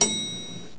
Elevbutn.wav